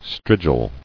[strig·il]